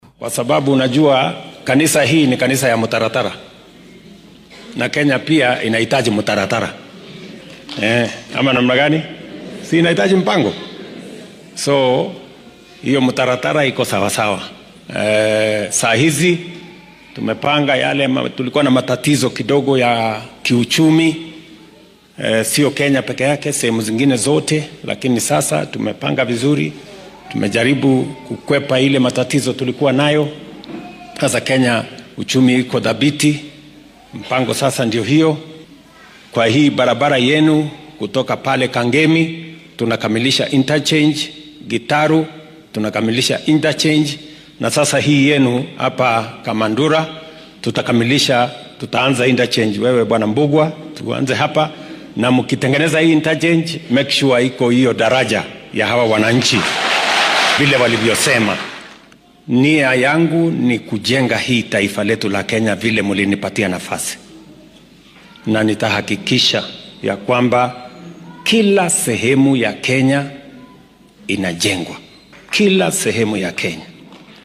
Dhanka kale ,madaxweyne William Ruto oo maanta munaasabad kaniiseed uga qayb galay deegaanka Limuru ee ismaamulka Kiambu ayaa difaacay waxqabadka maamulkiisa. Waxaa uu xusay inuu xaqiijin doono in horumar laga sameeyo qayb kasta oo dalka ka mid ah.